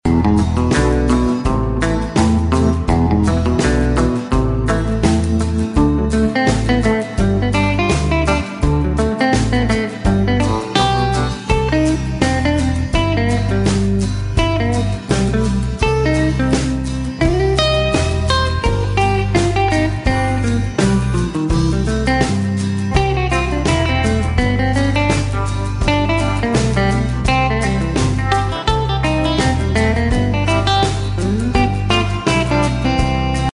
mastered and digitized in stereo
great retro sounds with modern grooves.